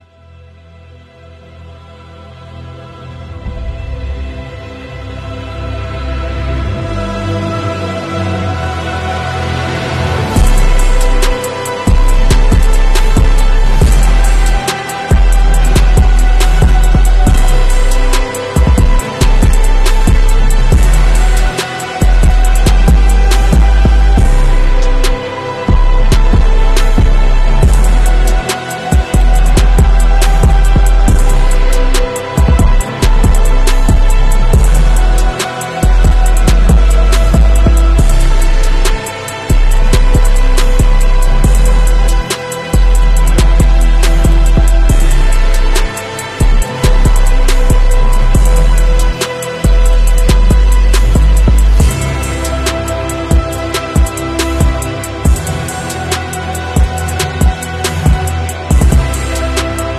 Moses opening the red sea sound effects free download